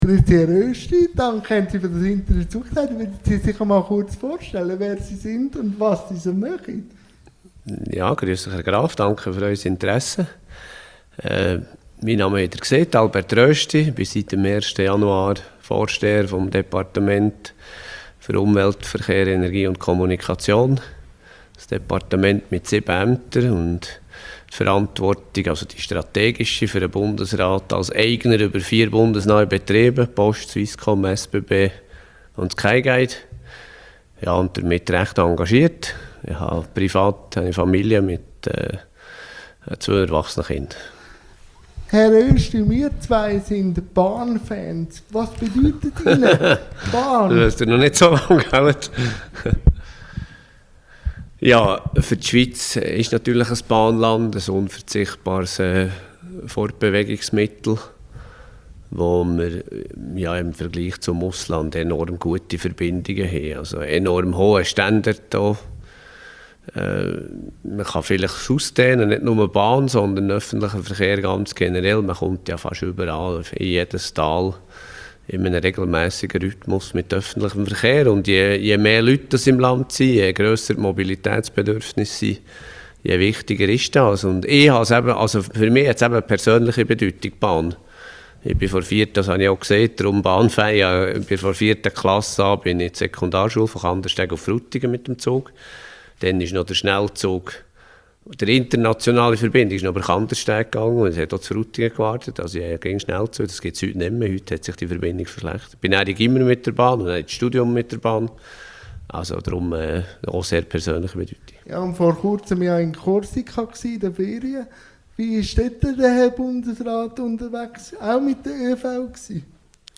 Bundesrat Albert Rösti INTERVIEW-THEMEN | Bahn, Führung und politische Teilhabe LINKS